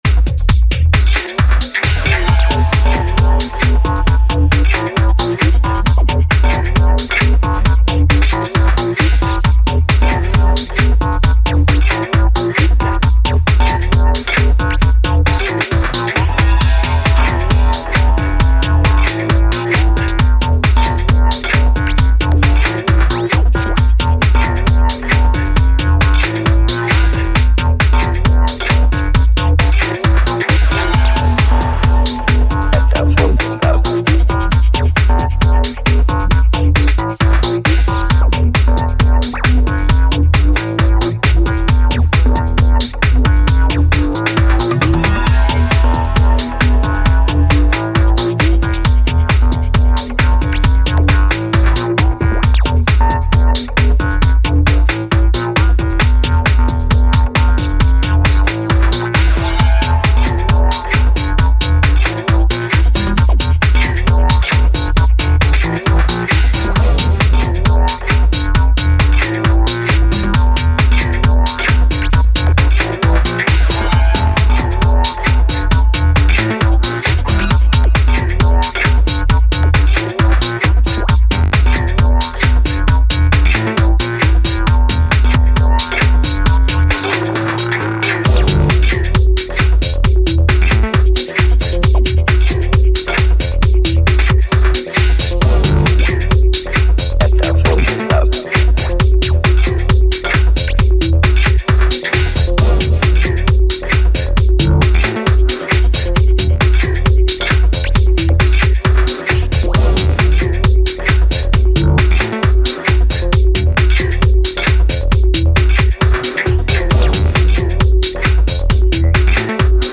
These drums weren’t simply made.
It boasts a peculiar flow and a three verse arrangement.